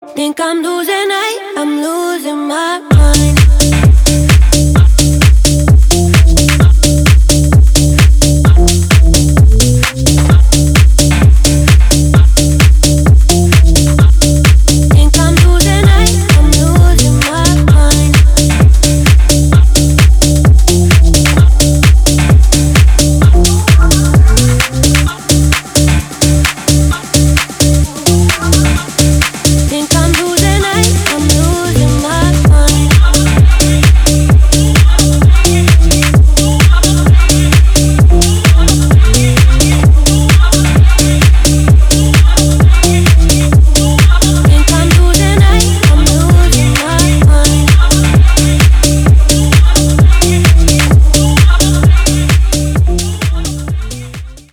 громкие
remix
deep house
dance
Club House
клубная музыка